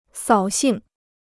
扫兴 (sǎo xìng) พจนานุกรมจีนฟรี